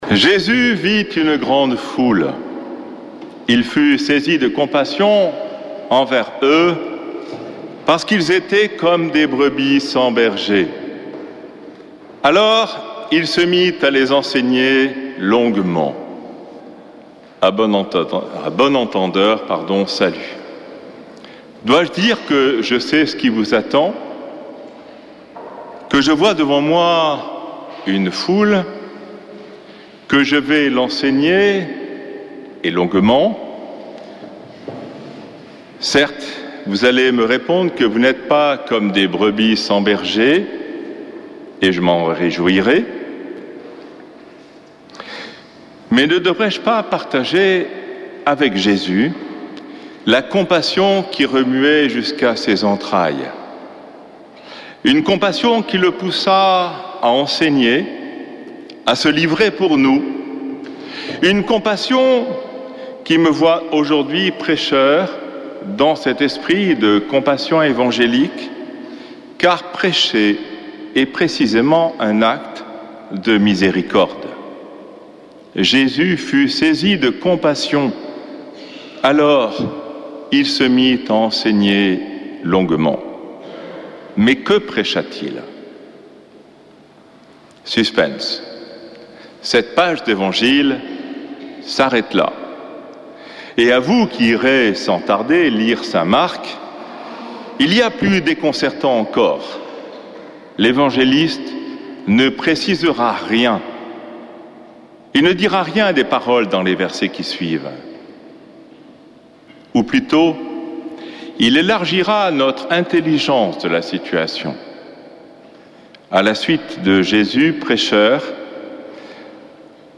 dimanche 21 juillet 2024 Messe depuis le couvent des Dominicains de Toulouse Durée 01 h 30 min
Homélie du 21 juillet